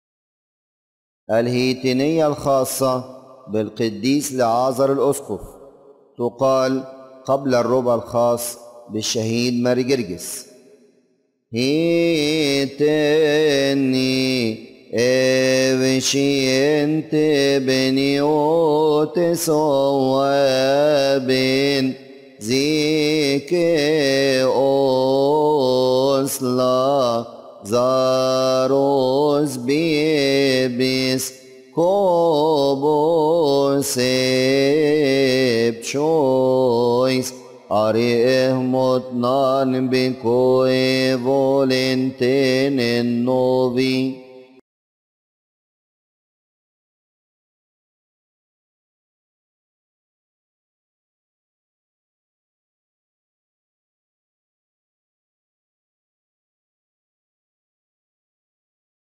لحن: هيتينية القديس لعازر